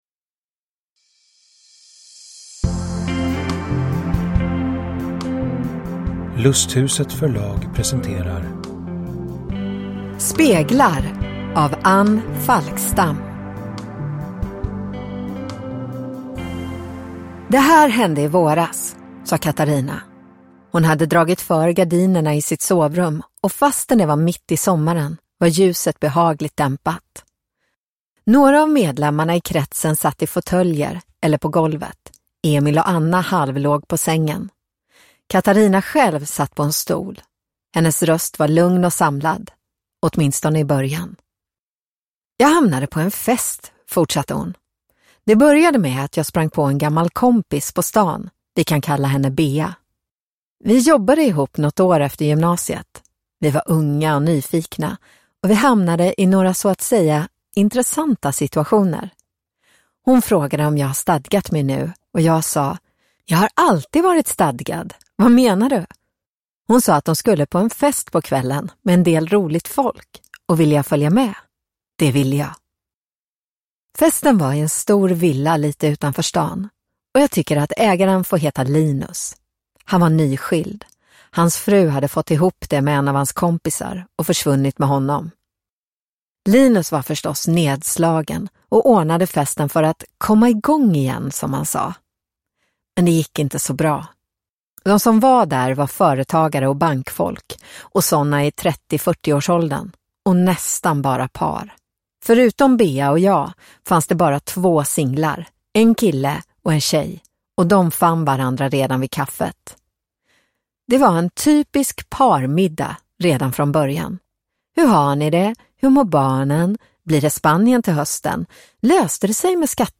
Speglar (ljudbok) av Anne Falkstam